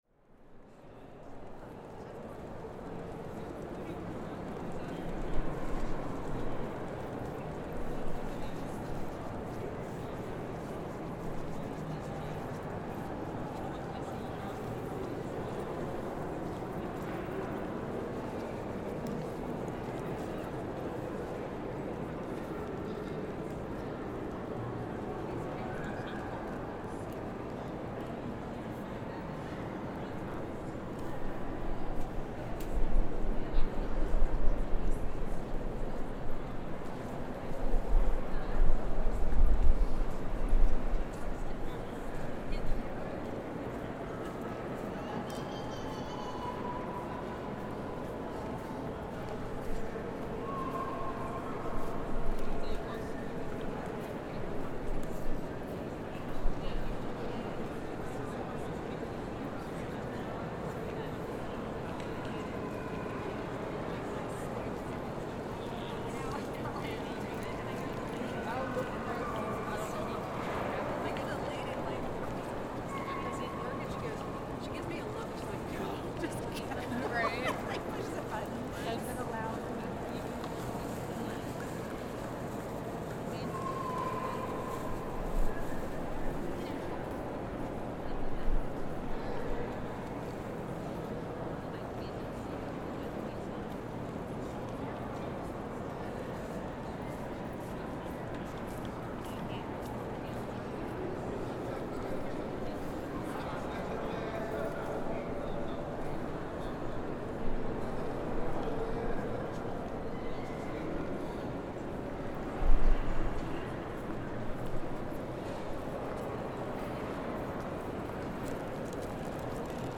Union Station (Washington DC) Field Recording